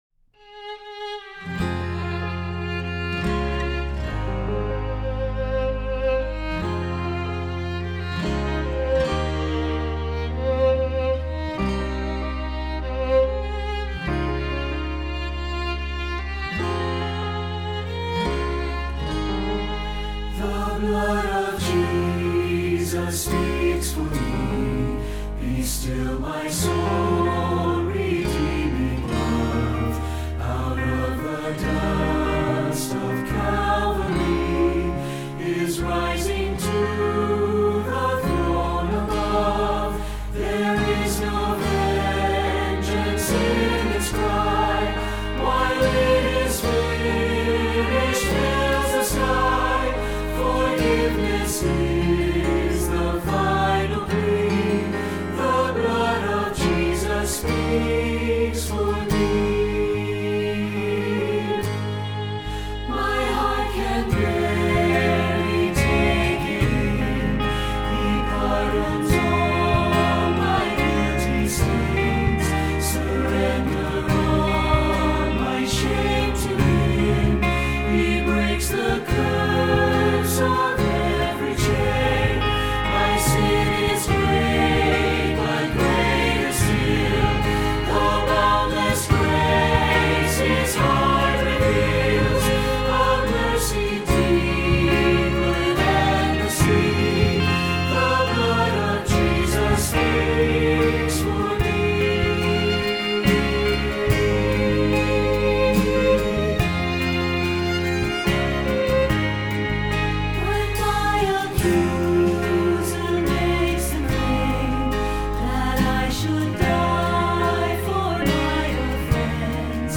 Voicing: SATB and Violin